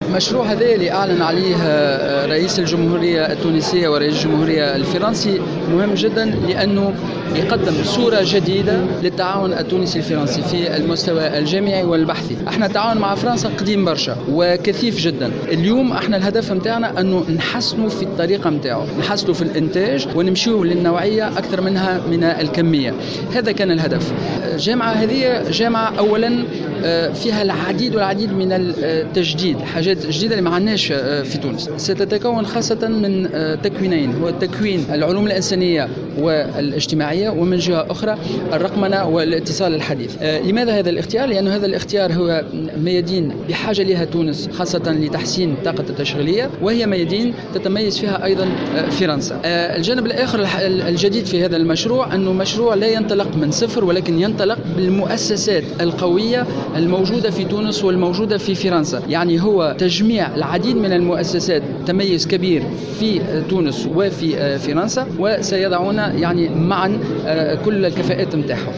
وأفاد وزير التعليم العالي، في تصريح إعلامي أن هذه الجامعة هي مشروع ضخم تقدر كلفته بنحو 30 مليون يور، موضحا أنها تطمح إلى استقطاب كل الطلبة الفرنكوفونيين، وخاصة الافارقة منهم.